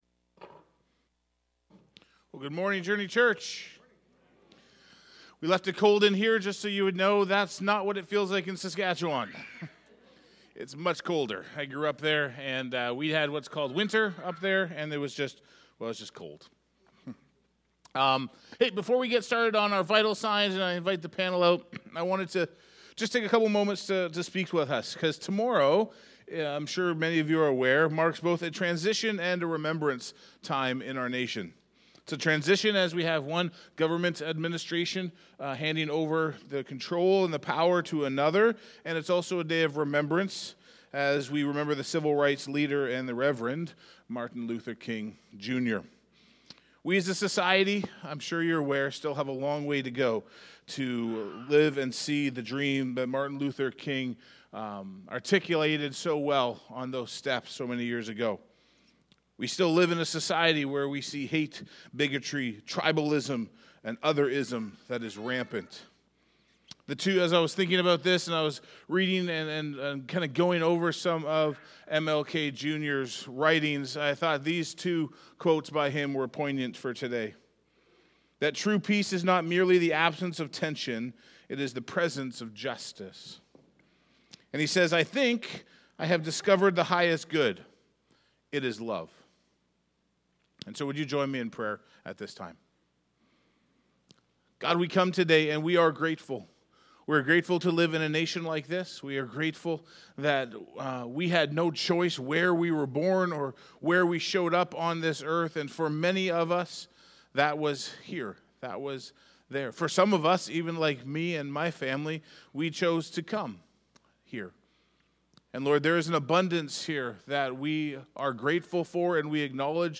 * We apologize for the technical difficulties during the last few seconds of this audio recording.